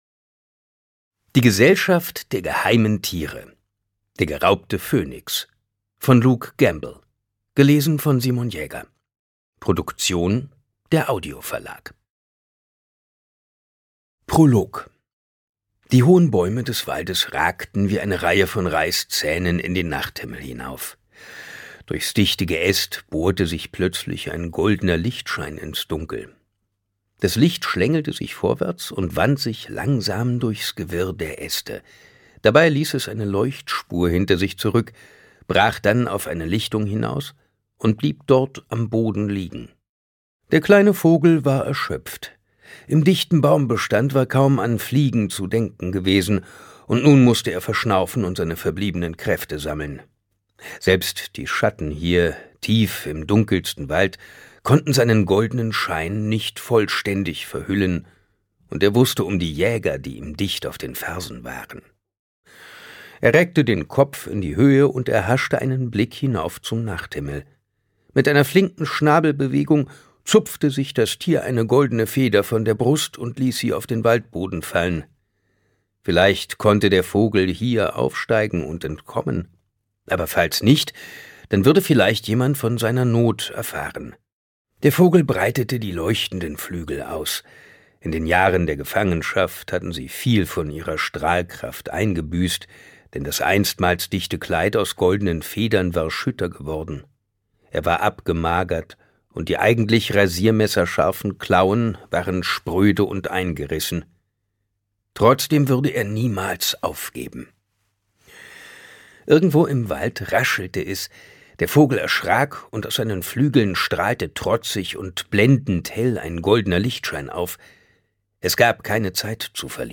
Ungekürzte Lesung mit Simon Jäger (1 mp3-CD)
Simon Jäger (Sprecher)
Simon Jäger versteht es meisterhaft, mit seiner Stimme unterschiedliche Stimmungen zu inszenieren.